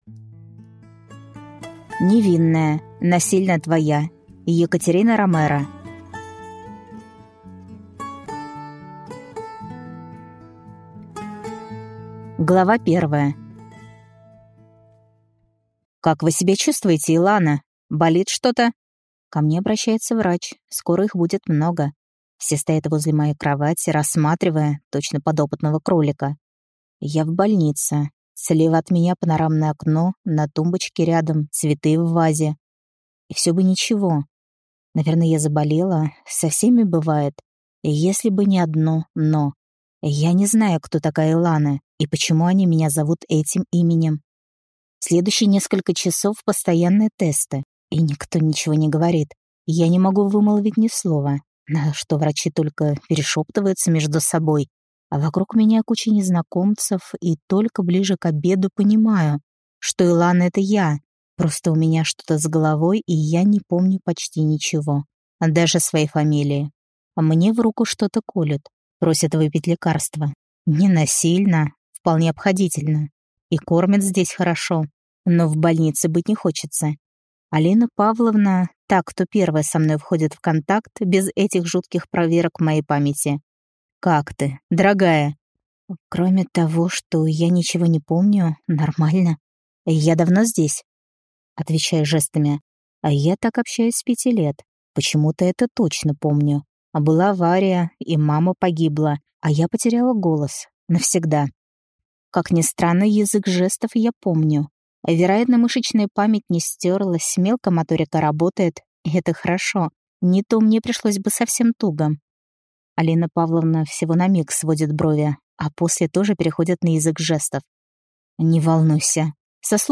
Мой механический роман (слушать аудиокнигу бесплатно) - автор Алексин Фолмут Фэролл